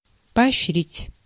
Ääntäminen
UK : IPA : /ə.ˈbɛt/ US : IPA : /ə.ˈbɛt/